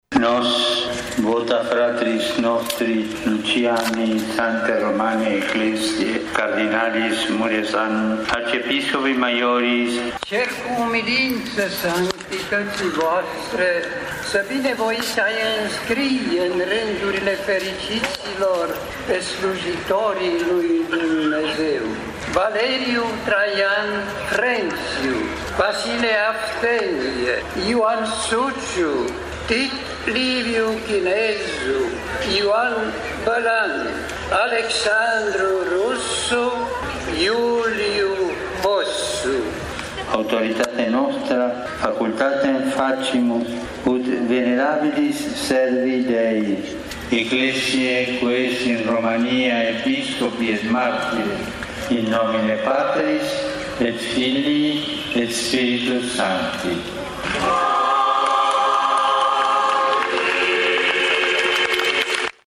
Pe 2 iunie el s-a aflat la Blaj, unde, pe Câmpia Libertății, a beatificat șapte episcopi martiri ai Bisericii Greco-Catolice.